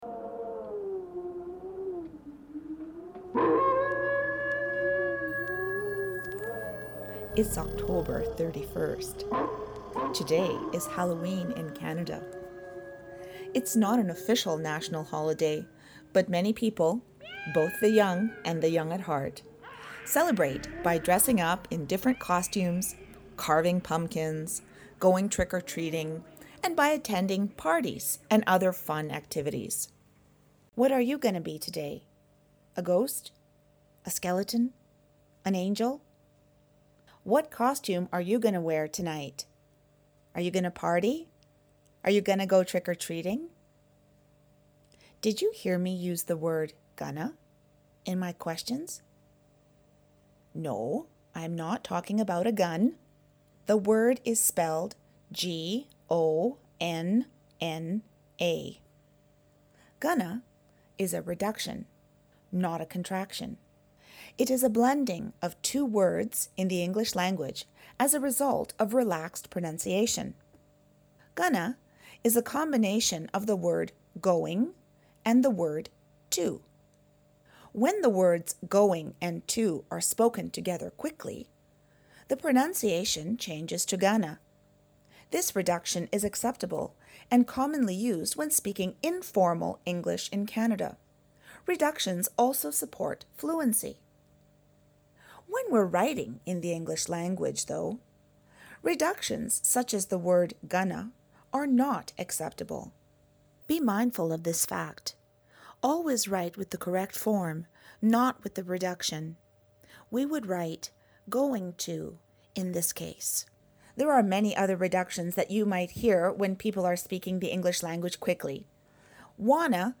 Four popular Hallowe’en songs use the word “gonna“, a relaxed pronunciation of the words “going” and “to”. Click on the audio link within the post to listen to the mini-lesson.